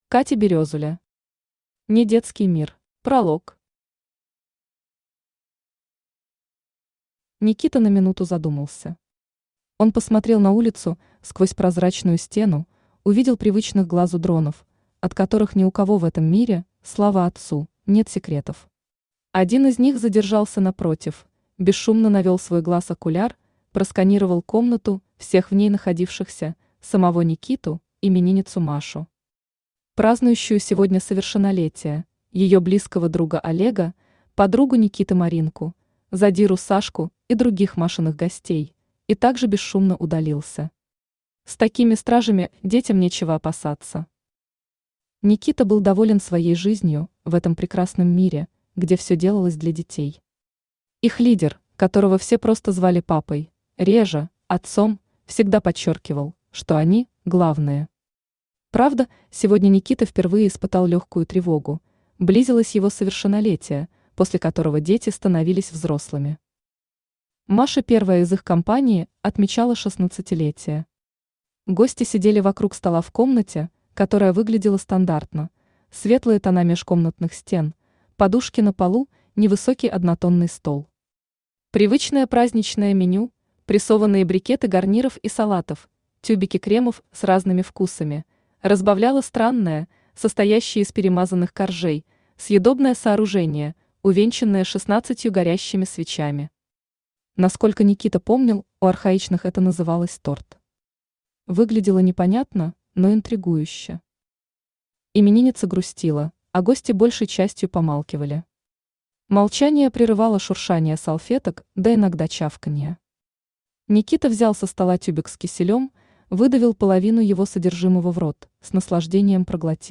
Аудиокнига неДетский мир | Библиотека аудиокниг
Aудиокнига неДетский мир Автор Катя Березуля Читает аудиокнигу Авточтец ЛитРес.